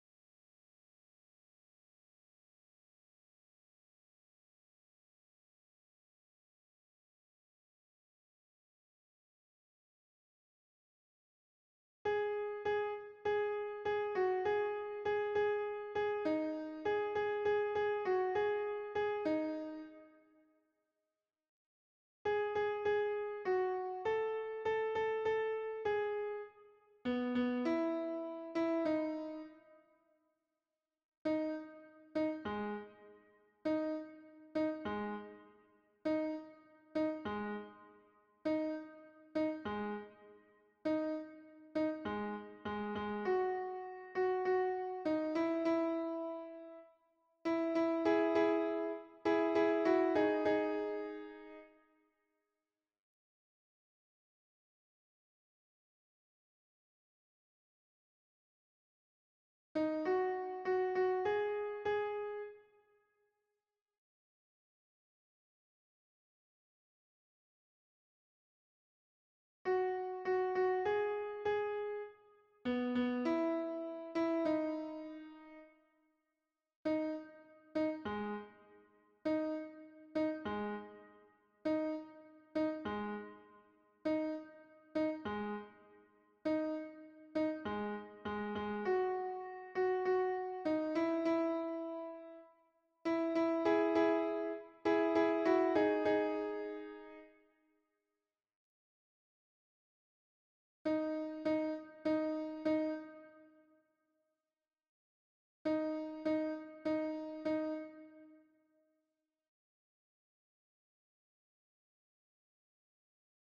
- berceuses juive séfarade
MP3 version piano
Soprano piano